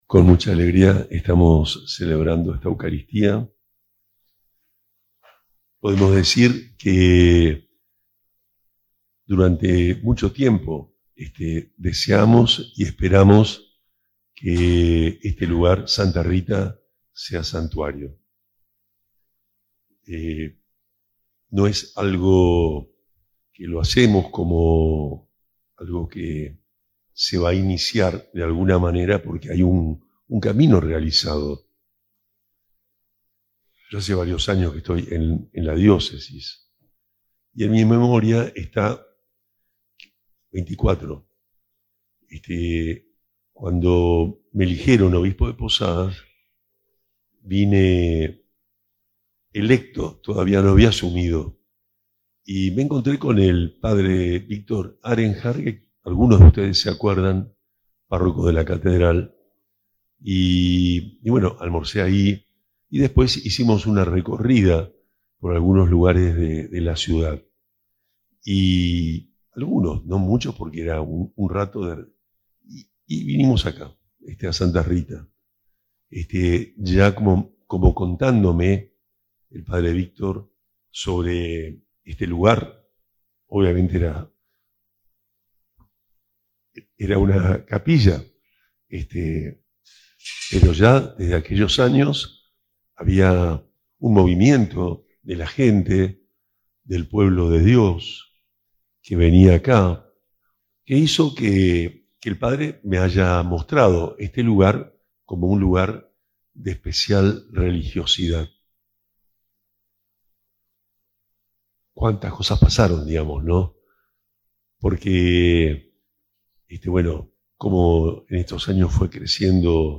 HOMILIA-OBISPO-SANTUARIO-SANTA-RITA.mp3